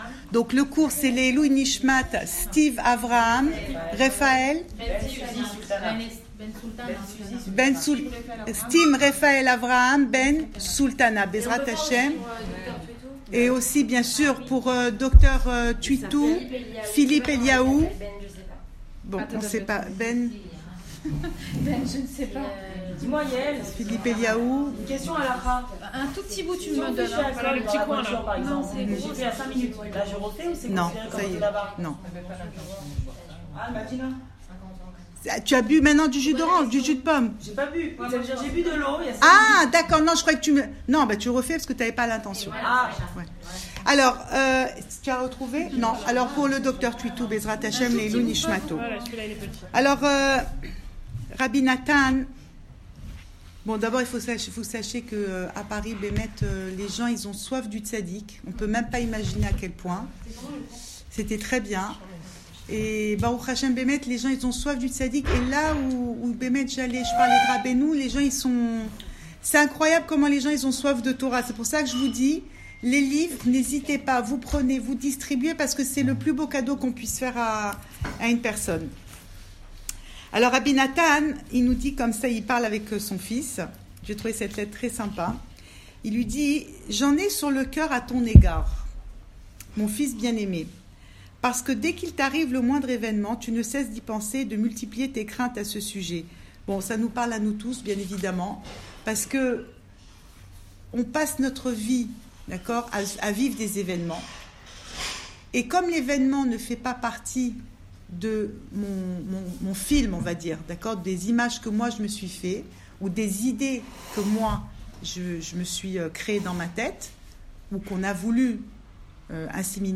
Cours audio
Enregistré à Raanana